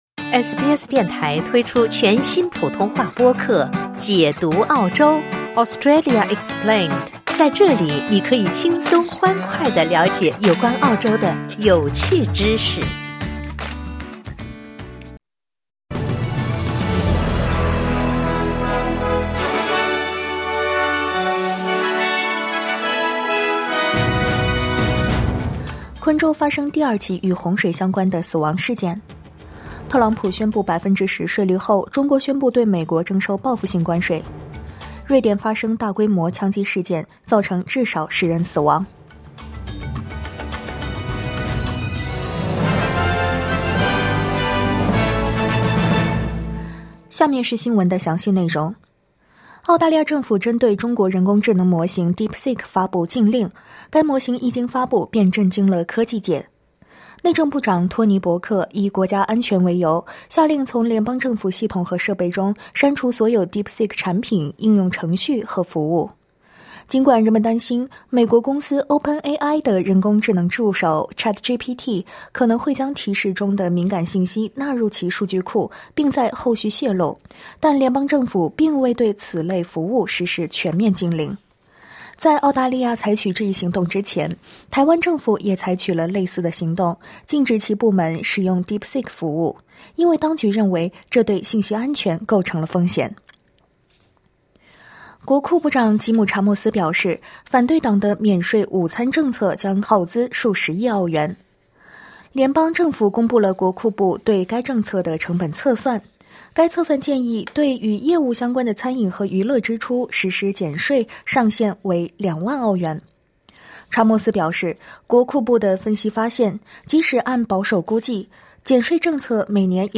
SBS早新闻（2025年2月5日）